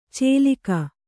♪ cēlika